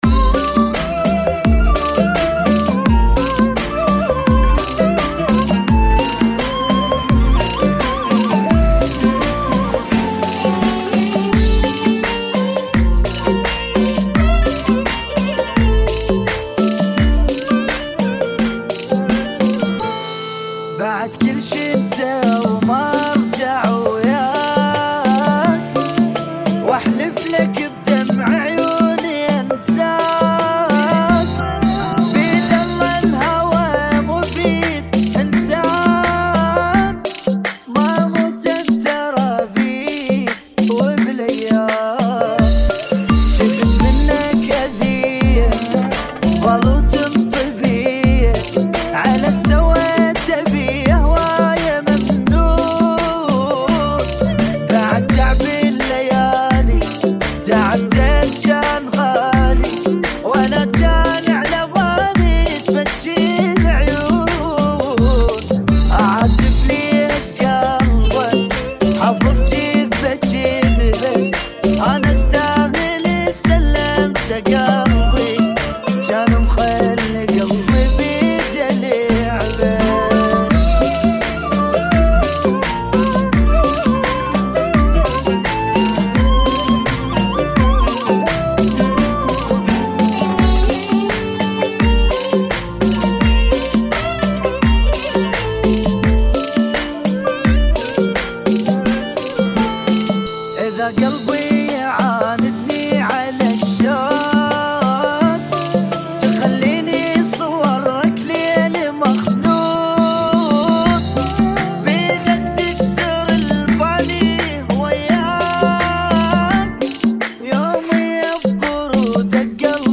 [ 85 bpm ]